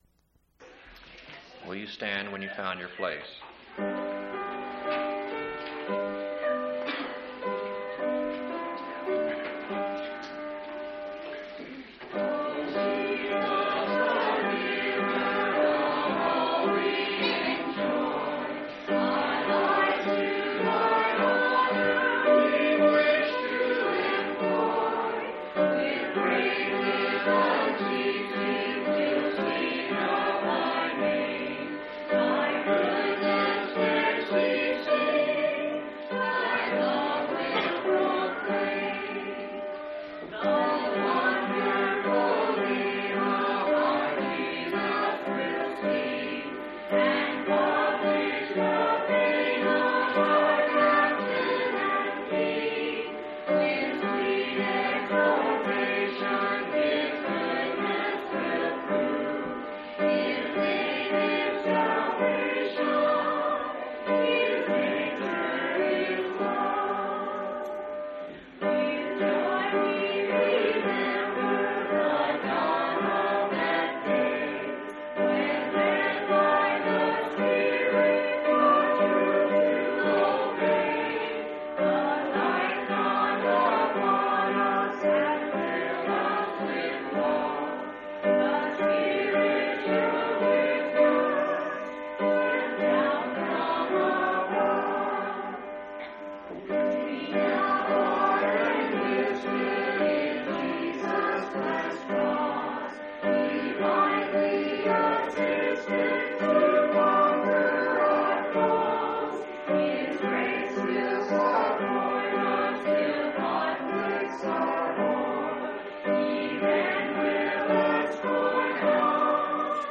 11/17/1991 Location: Phoenix Local Event